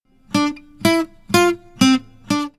guitar.mp3